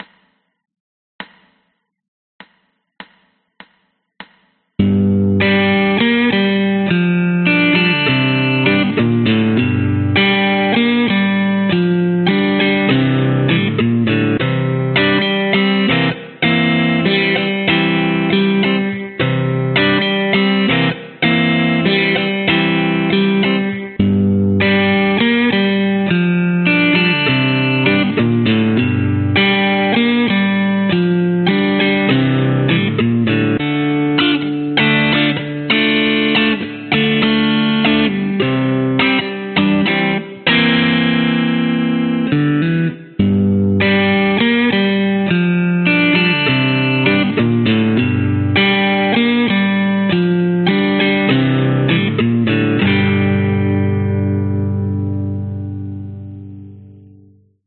标签： 放克 蓝调 吉他 电动 电吉他 Telecaster 男声 原创歌曲